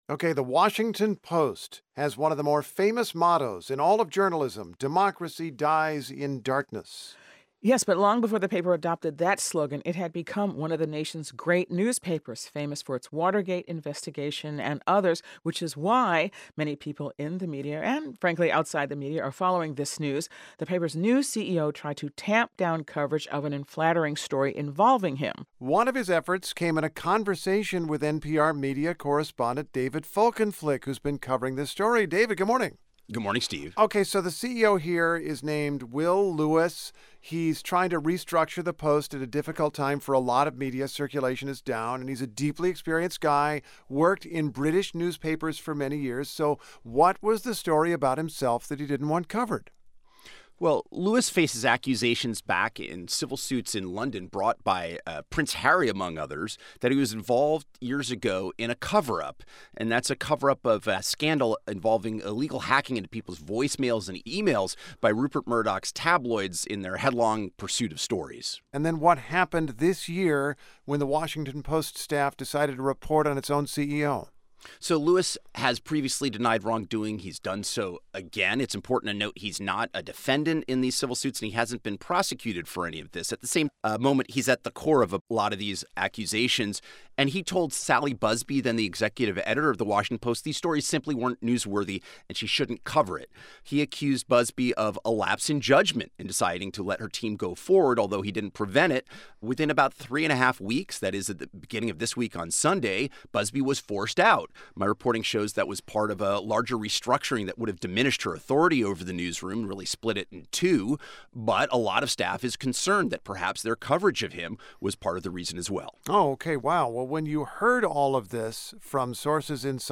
Analysis